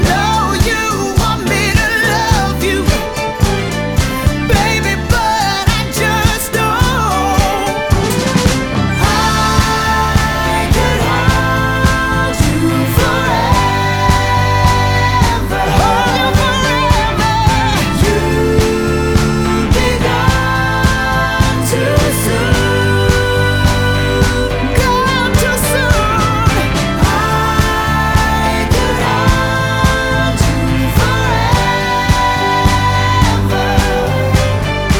# Jazz